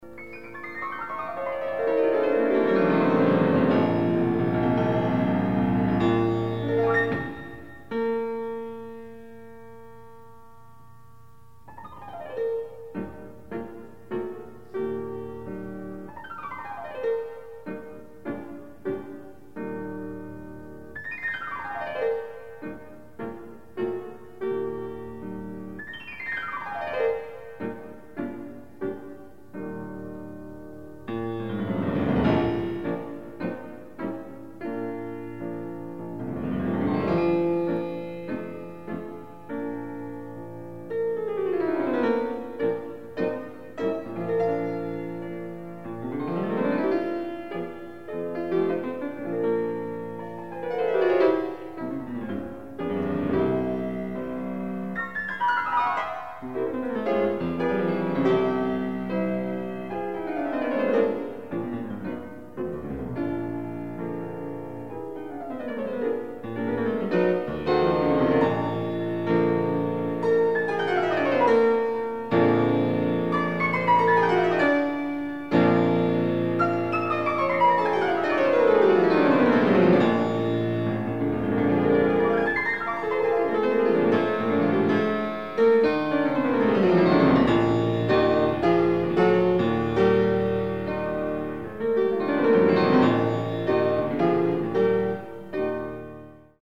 Additional Date(s)Recorded September 13, 1977 in the Ed Landreth Hall, Texas Christian University, Fort Worth, Texas
Short audio samples from performance